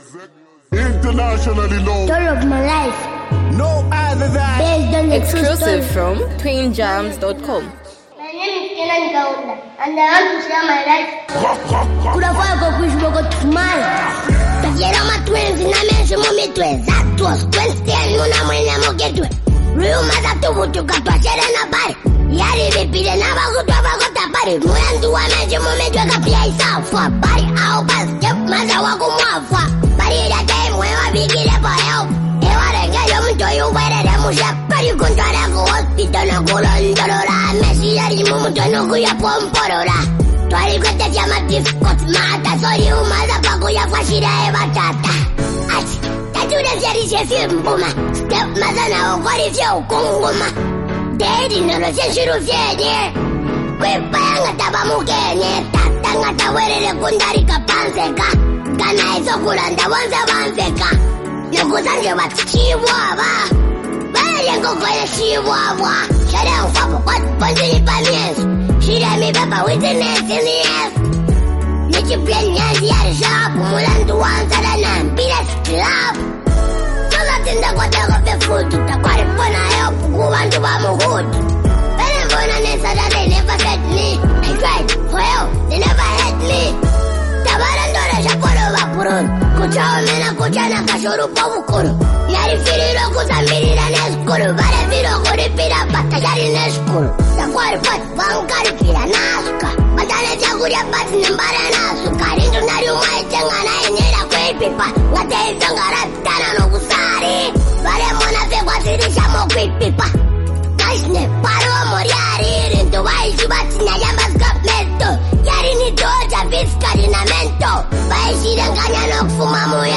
reflective and emotionally grounded song